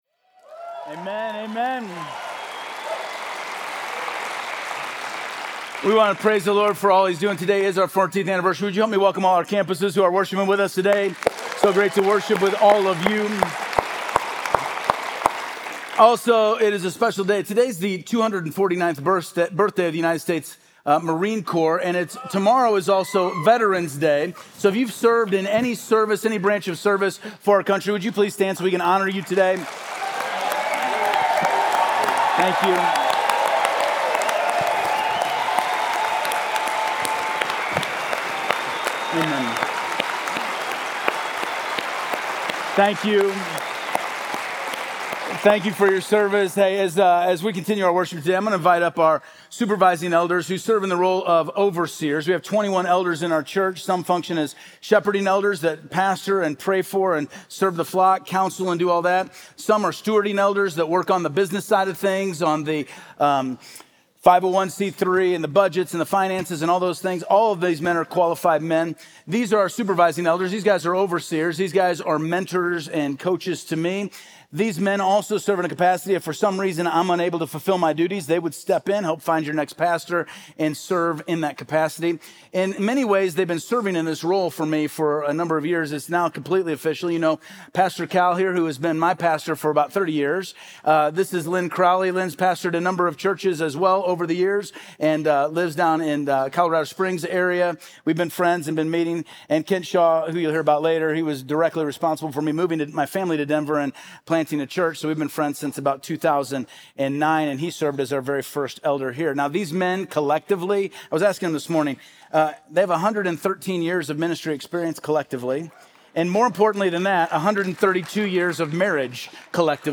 He emphasizes the importance of understanding the times by looking up to God in worship, looking back with gratitude for His faithfulness, and looking forward with faith in His promises. Throughout the sermon, he shares testimonies of God’s continued work in the lives of the congregation and the community.